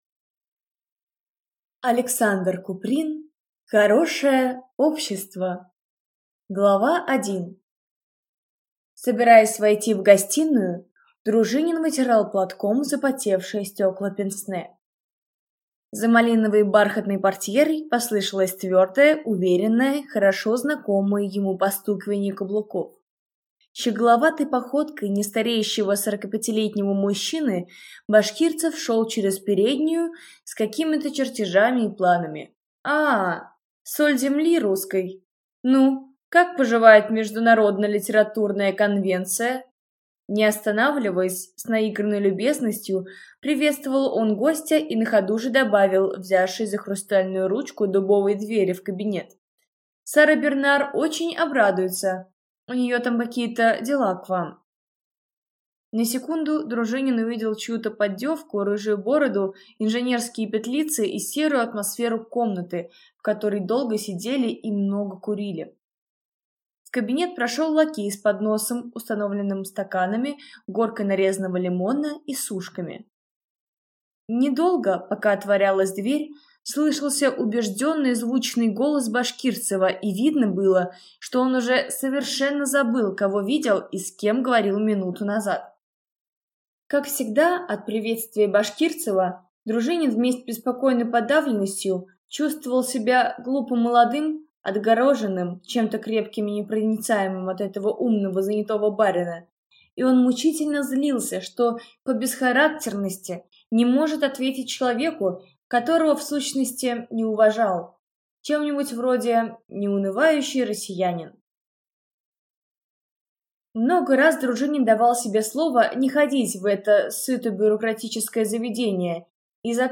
Аудиокнига Хорошее общество | Библиотека аудиокниг